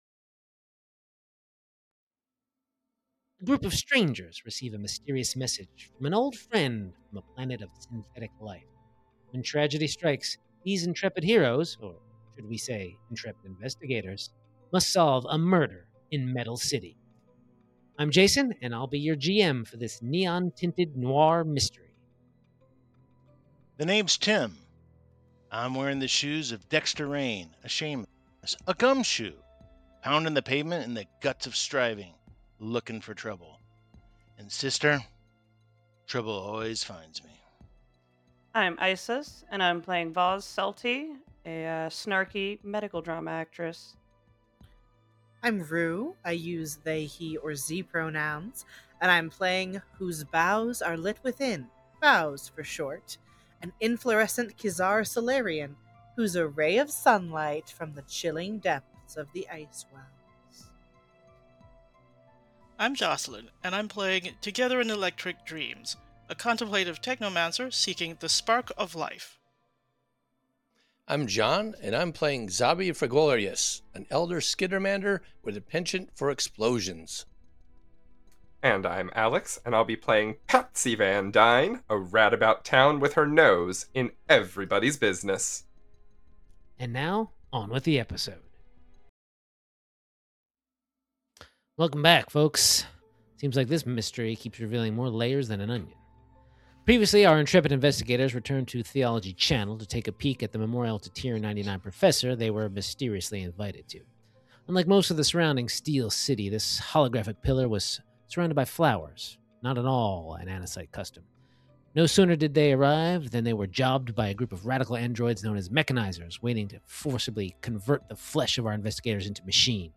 Actual Play Podcast